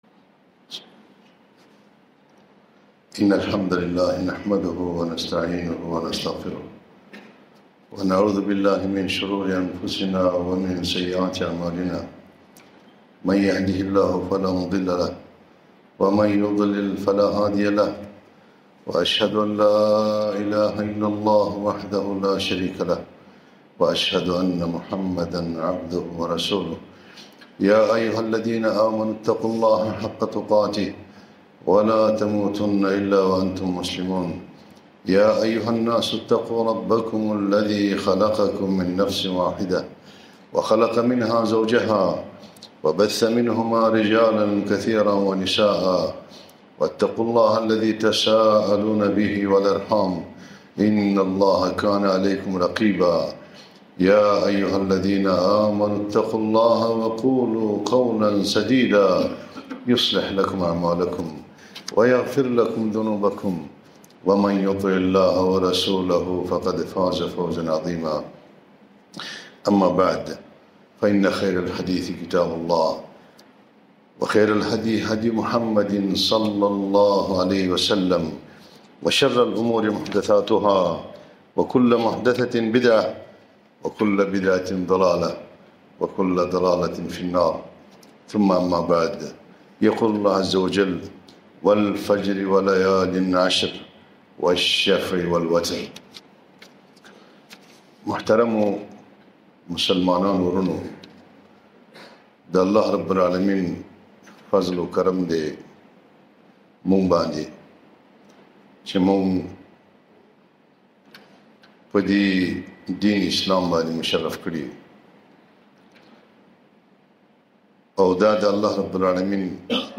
خطبة - فضل الأيام العشر من ذي الحجة (بلغة البشتو)